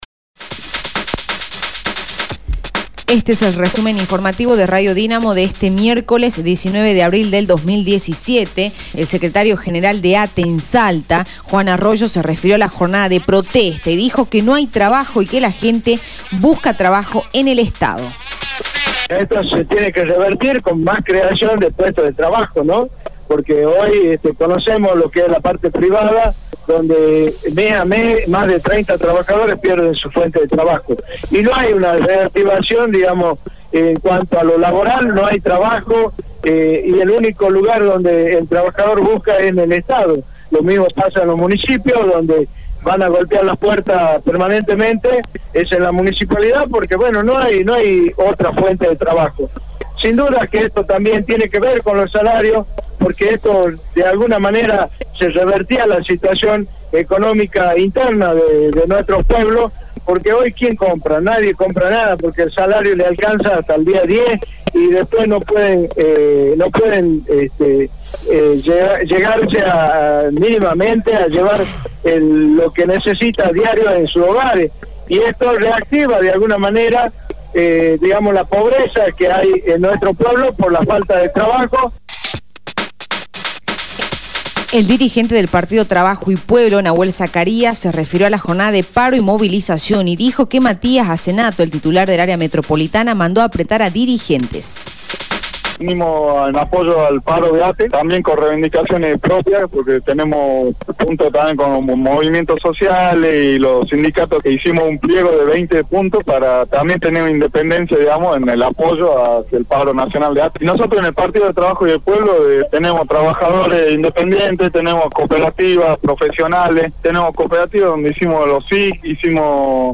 Resumen Informativo de Radio Dinamo del día 19/04/2017 1° Edición